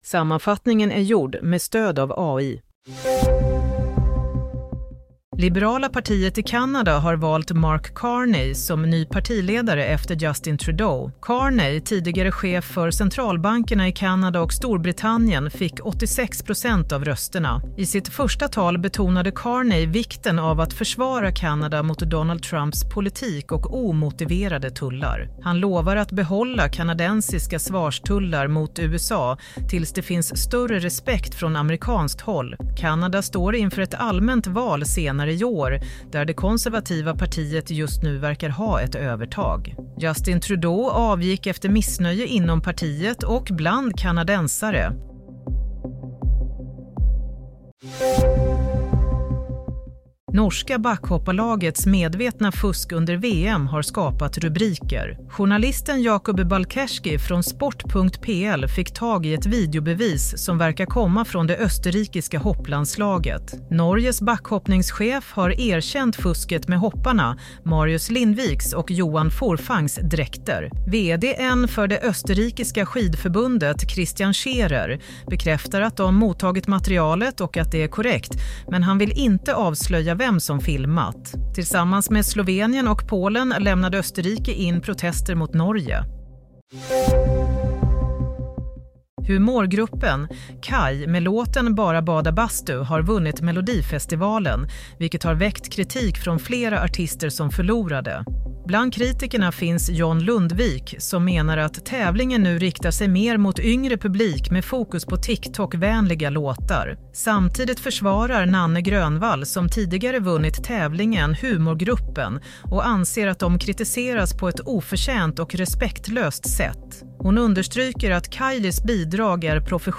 Play - Nyhetssammanfattning – 10 mars 07:00
Sammanfattningen av följande nyheter är gjorda med stöd av AI. – Kanadas nye ledare varnar för Trump – Österrike bemöter påståendet: ”Vill inte avslöja” – Nanne Grönvall försvarar KAJ efter kritiken Broadcast on: 10 Mar 2025